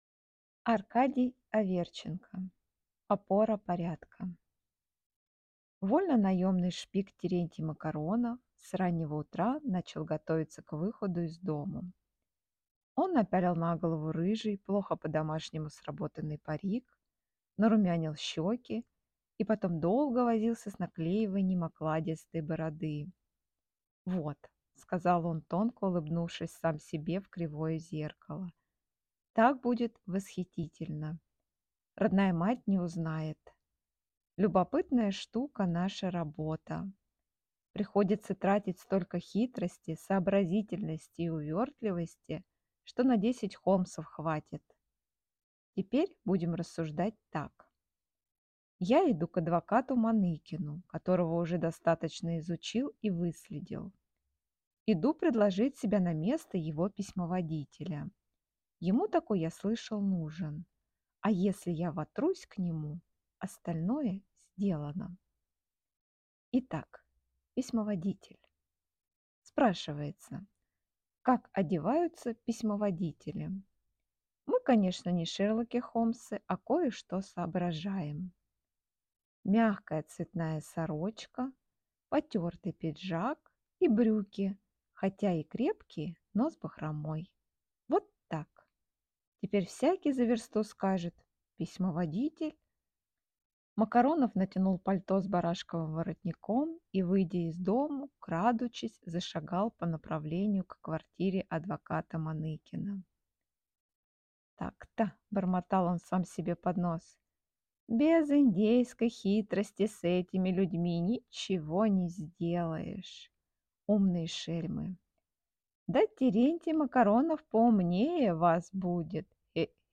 Аудиокнига Опора порядка | Библиотека аудиокниг